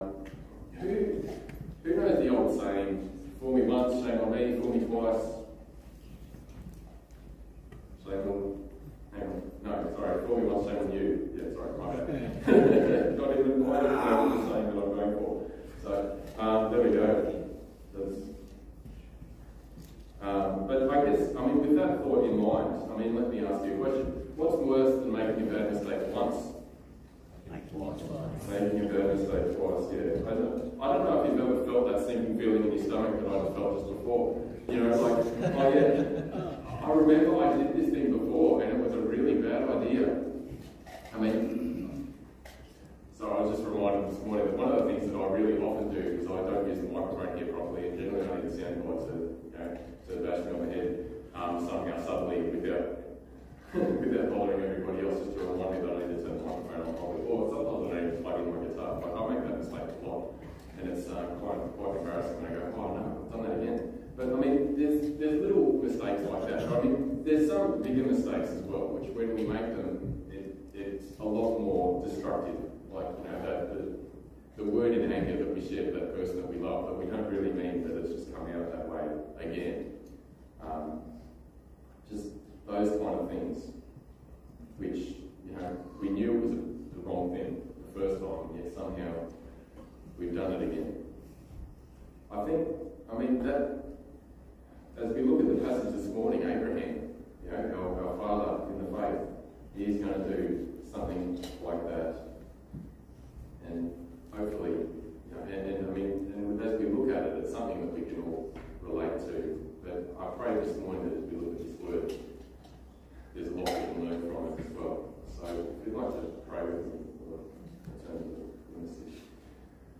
Passage: Genesis 20 Service Type: Sunday Morning